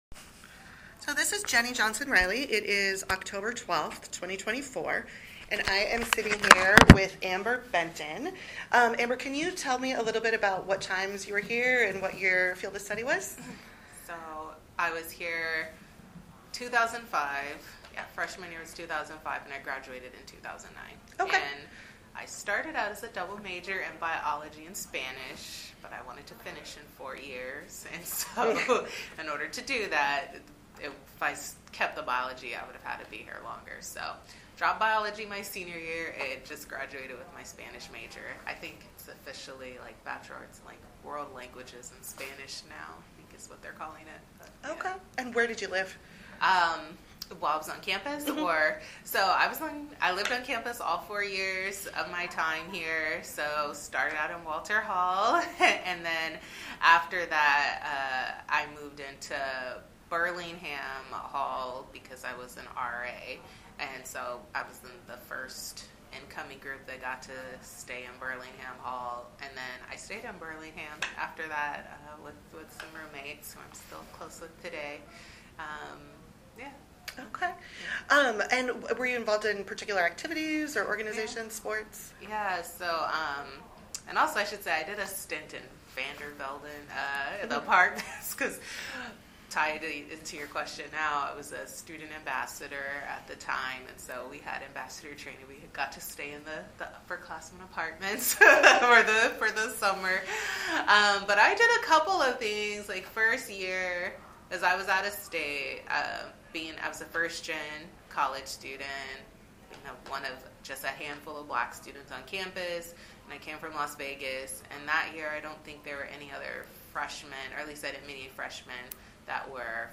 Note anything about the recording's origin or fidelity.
This is one of a group of recordings made during a reunion in October, 2024.